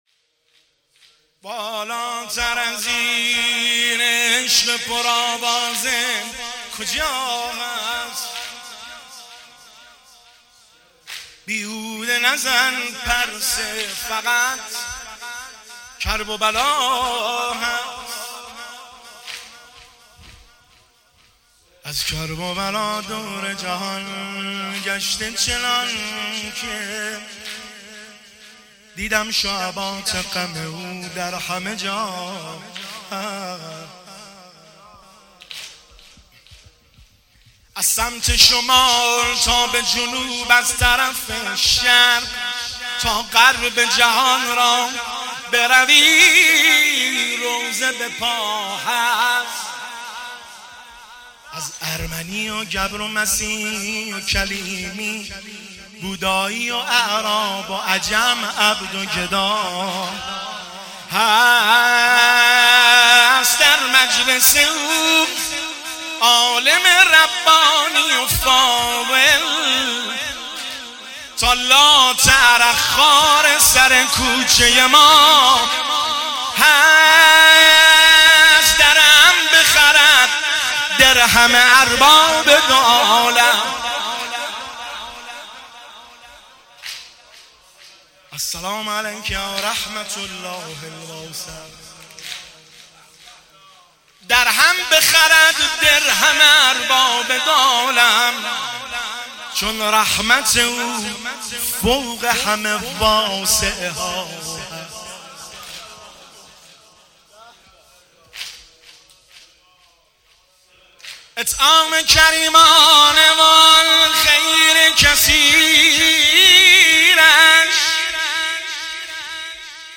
که در هیئت بین الحرمین طهران - جلسه خصوصی اجرا شده است.
شعرخوانی